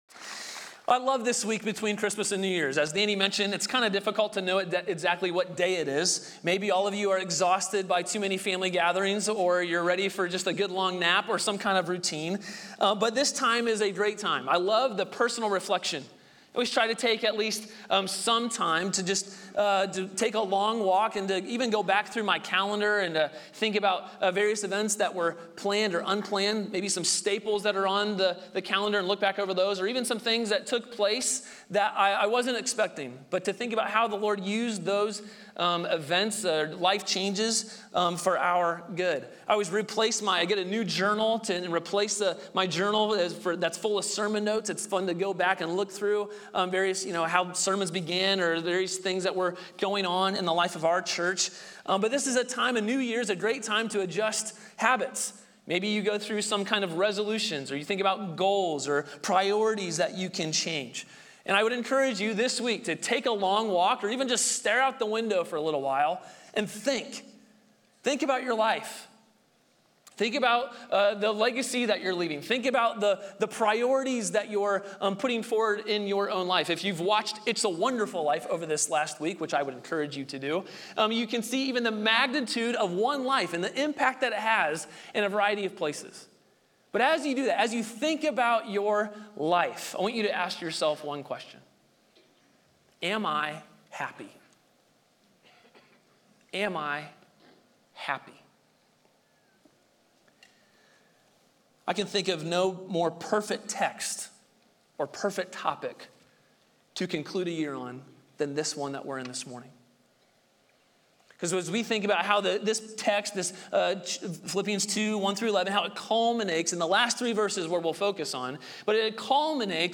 Sermons • Grace Polaris Church